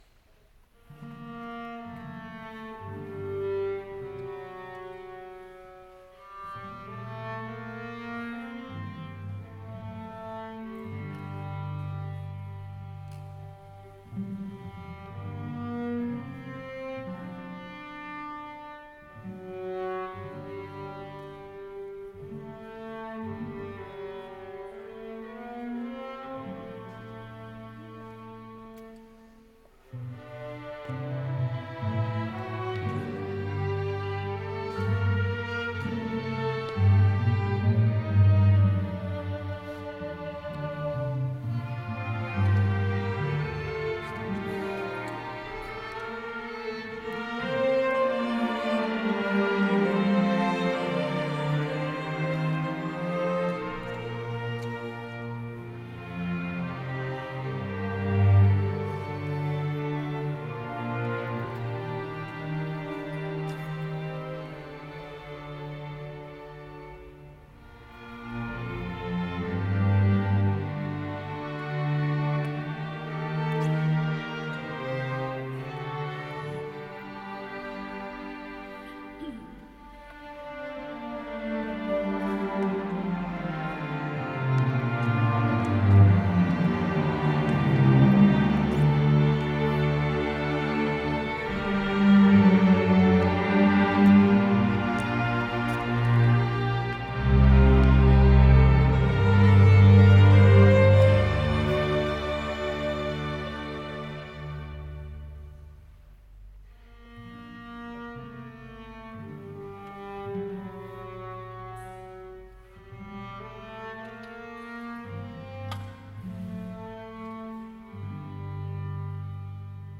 Quintencircel Dülmen